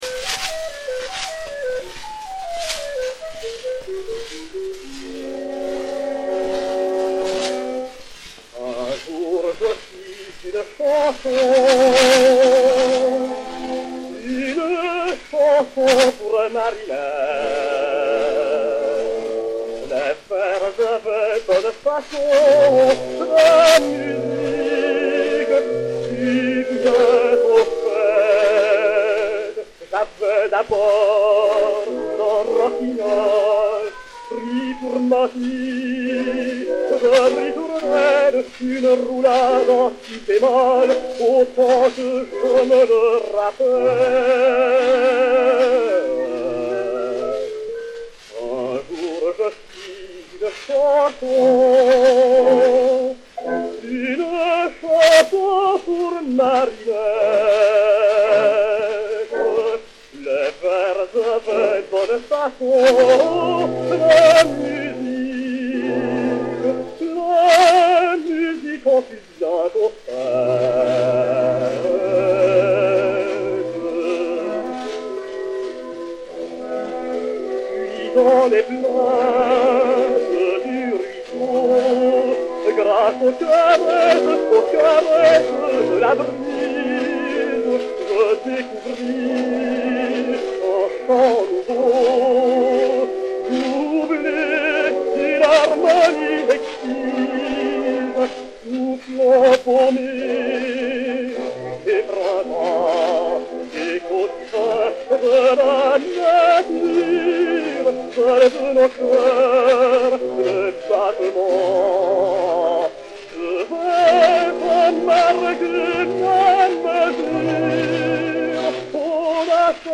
Orchestre
Zonophone X 82688, mat. 6648o, enr. à Paris en 1907